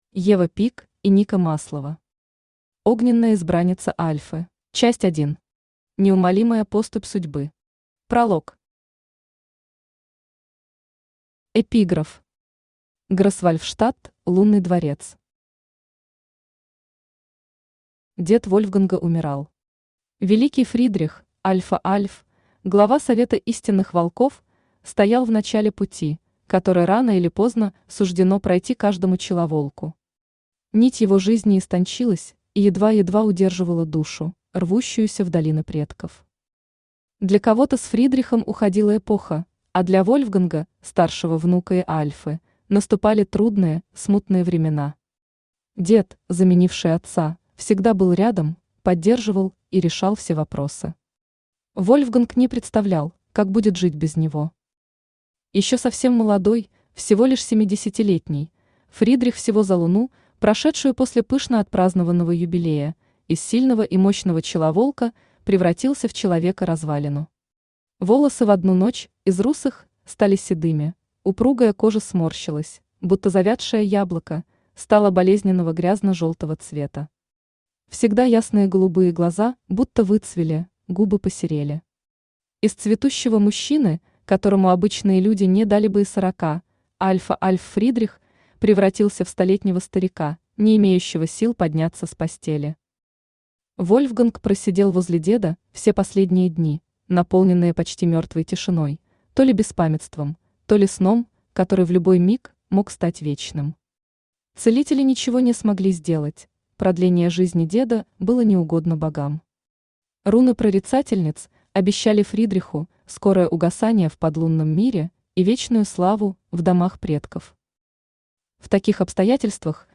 Аудиокнига Огненная избранница Альфы | Библиотека аудиокниг
Aудиокнига Огненная избранница Альфы Автор Ева Пик и Ника Маслова Читает аудиокнигу Авточтец ЛитРес.